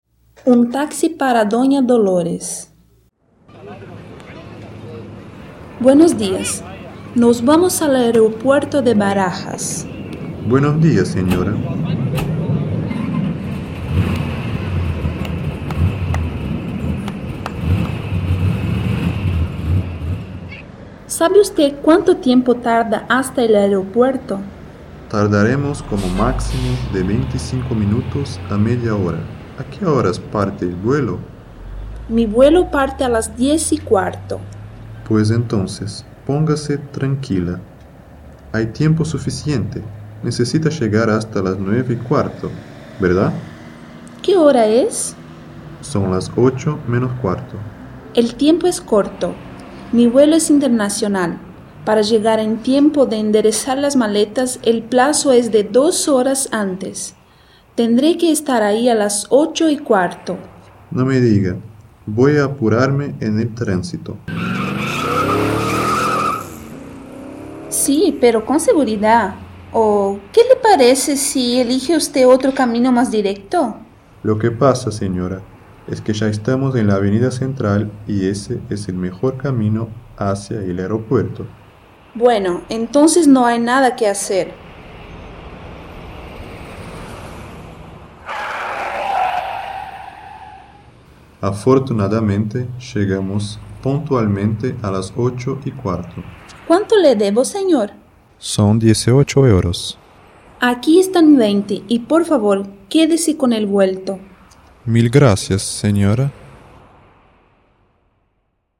Diálogo XIX: Um taxi para Dona Dolores
Description: Áudio do livro didático Língua Espanhola I, de 2008.